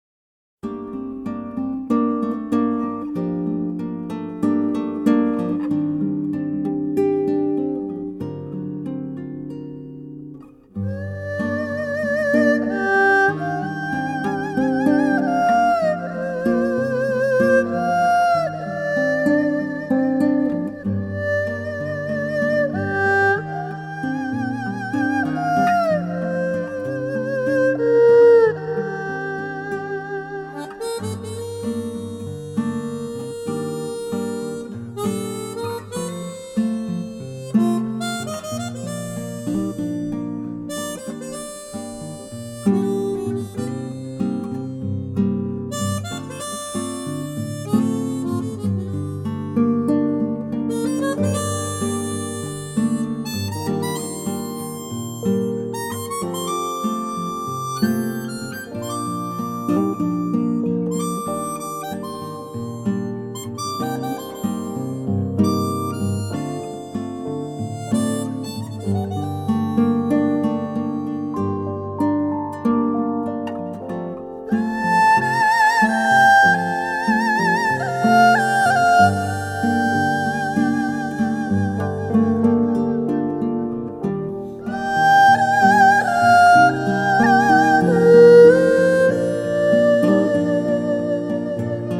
★ 在傳統樂音中注入古老而優美的全新生命力，帶來最令人愉悅撫慰的心靈詩篇！